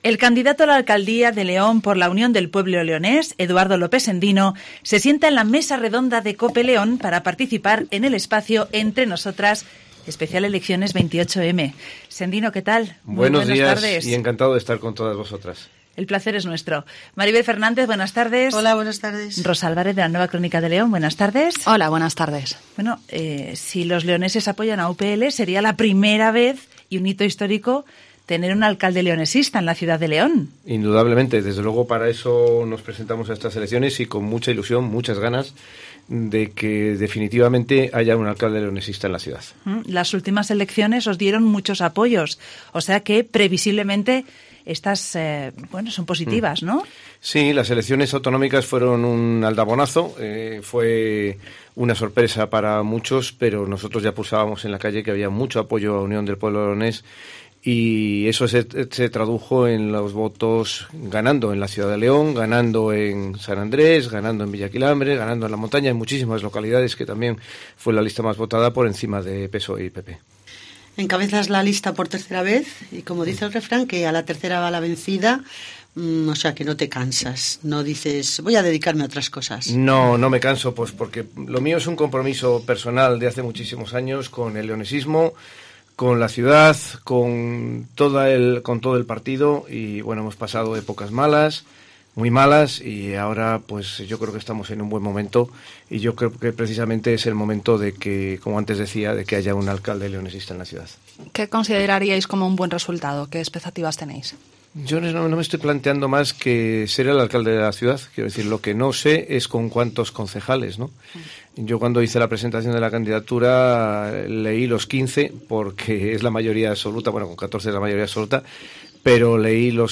El candidato a la alcaldía de León por Unión del Pueblo Leonés, Eduardo López Sendino, se sienta en la mesa redonda de Cope León para participar en el espacio “Entre Nosotras Especial Elecciones 28 M”.